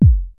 VCF BASE 1 1.wav